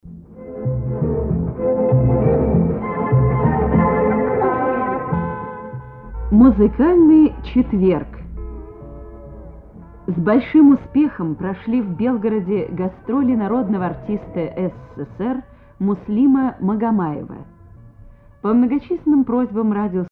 Фрагмент низкого качества, но другого, увы, нет.